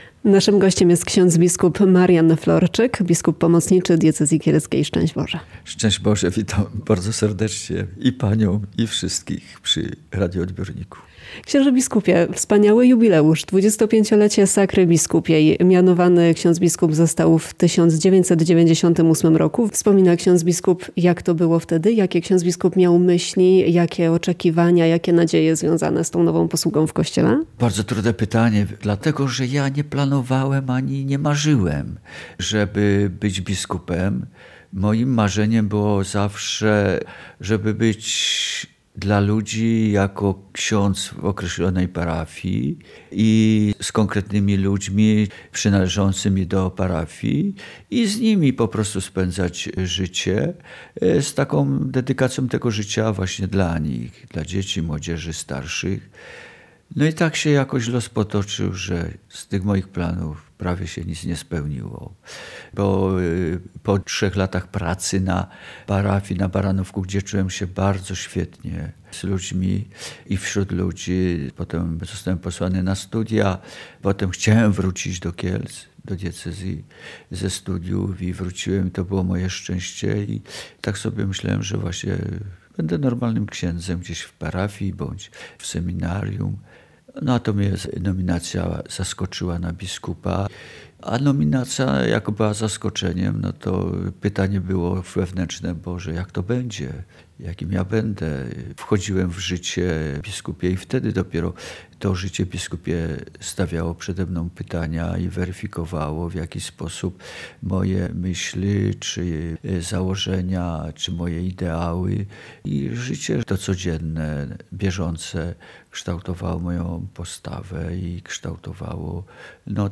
W rozmowie z Radiem Kielce szczerze przyznaje, że myślał wtedy raczej o pracy na parafii, niż o biskupstwie.
Bp-Marian-Florczyk-rozmowa-w-RK-z-okazji-25-lecia-sakry-biskupiej-online-audio-converter.com_.mp3